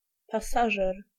Ääntäminen
IPA: [pa.sa.ʒe]